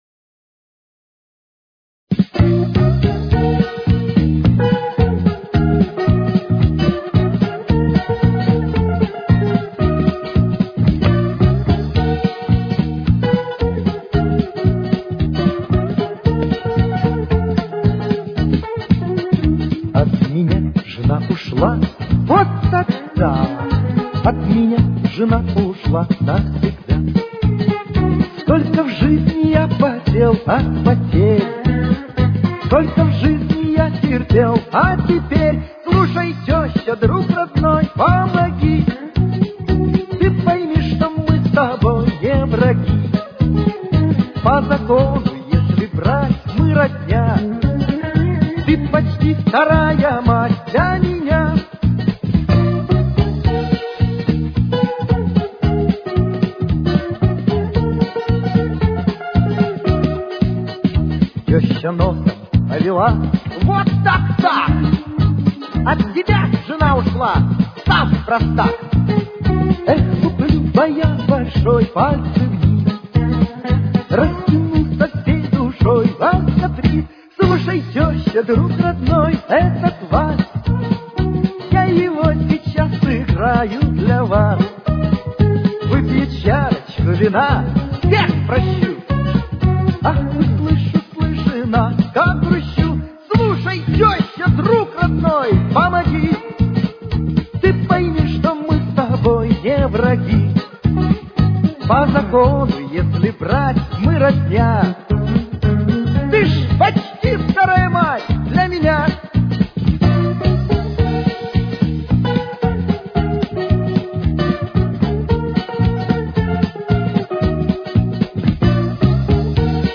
с очень низким качеством (16 – 32 кБит/с)